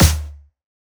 drum-hitfinish.wav